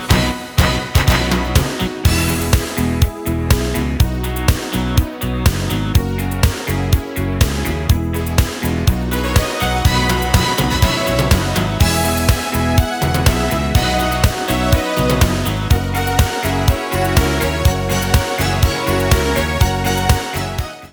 Hier geht’s zur Hörprobe der Karaoke-Version.